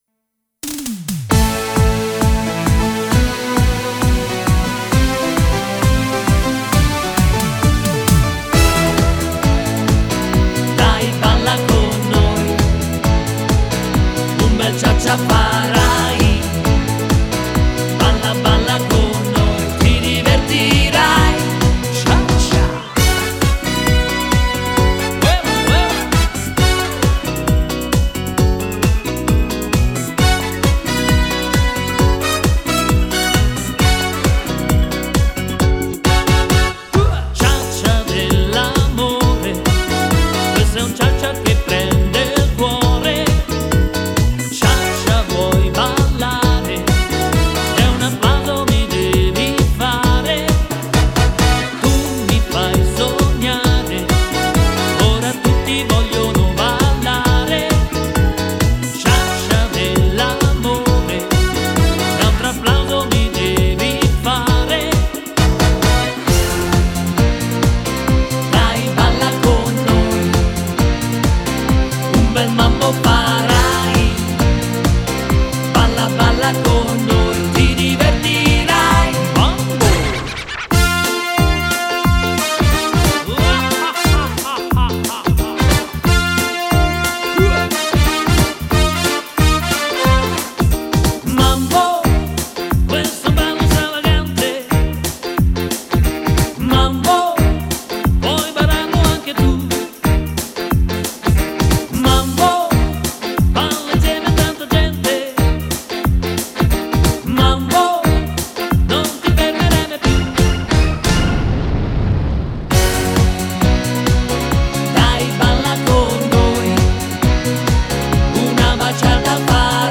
Ballo di gruppo